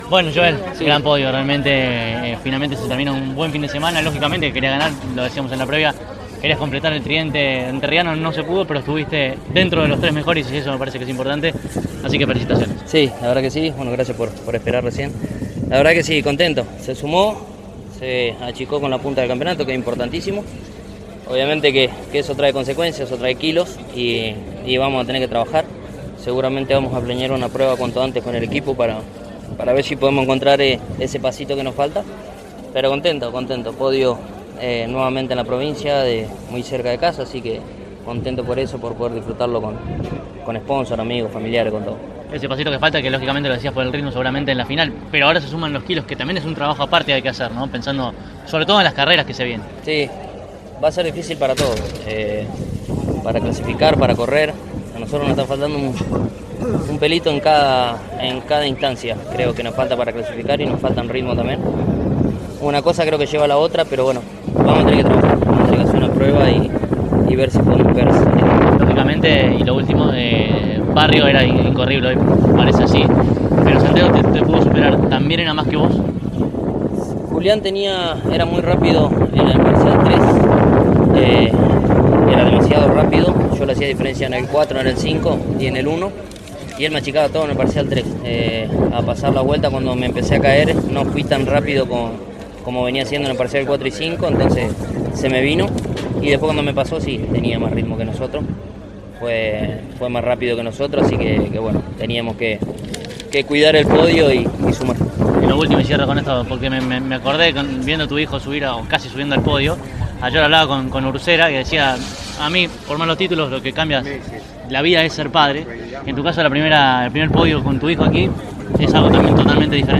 En una nueva cobertura de una competencia del TN de este medio, tanto los tres mejores de la final de la divisional mayor, como así también el mejor cordobés posicionado, fueron aquí entrevistados.